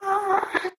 moan2.ogg